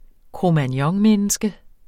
Udtale [ kʁomanˈjʌŋ- ]